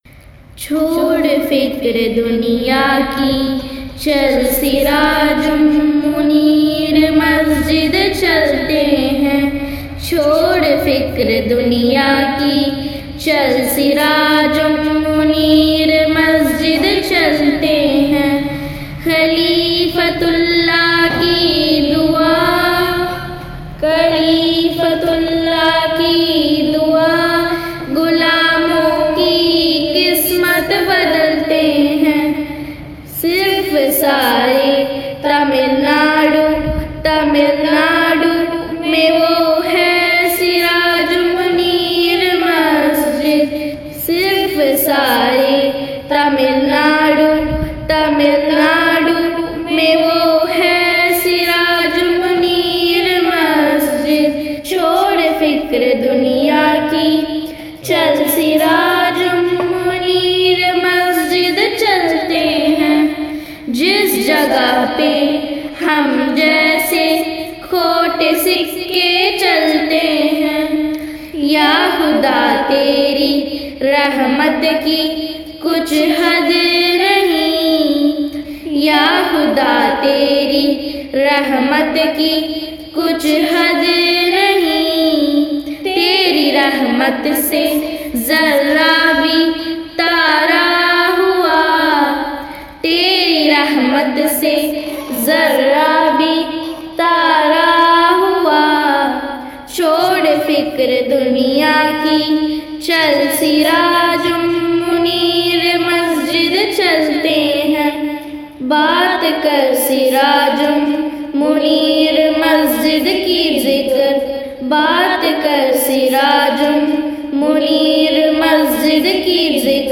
நஸம்